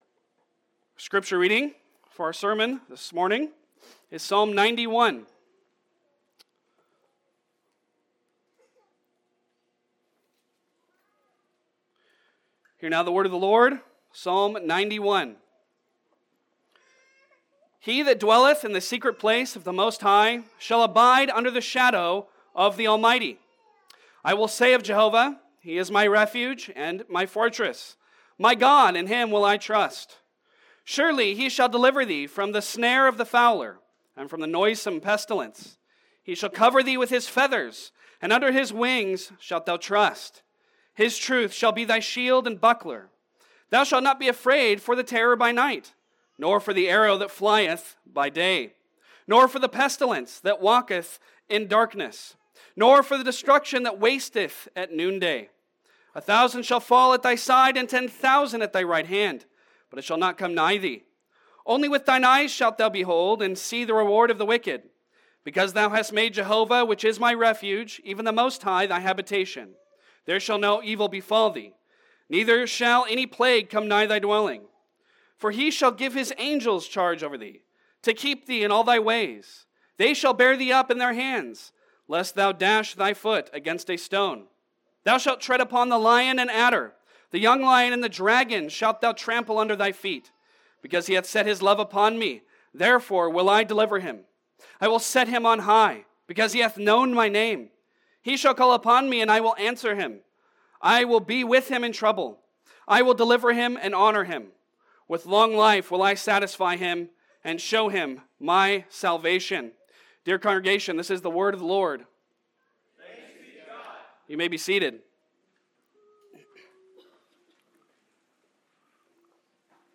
Passage: Psalm 91 Service Type: Sunday Sermon Download Files Bulletin « Washed By Christ’s Blood and Spirit What is the Church?